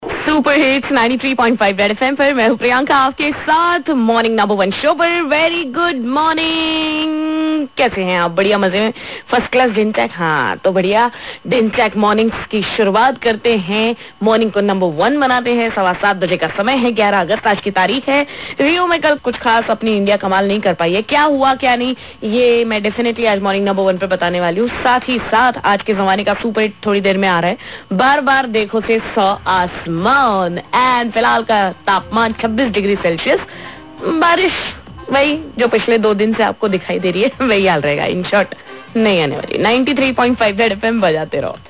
weather update